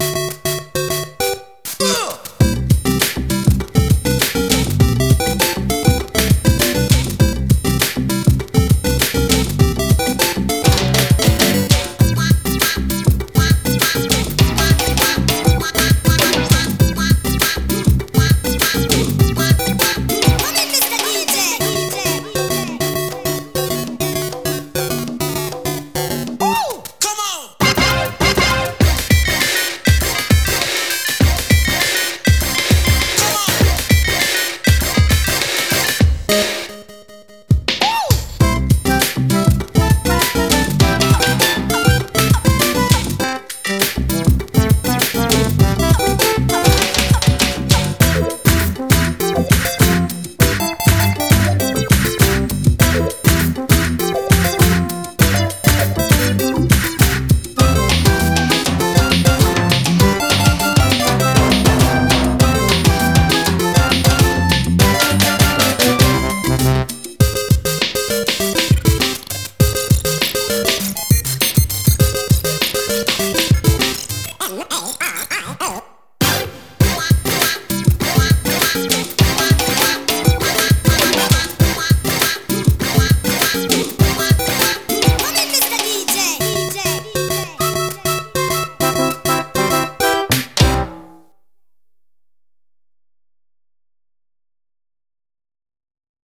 BPM100
Audio QualityPerfect (High Quality)
Better quality audio.